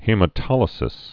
(hēmə-tŏlĭ-sĭs)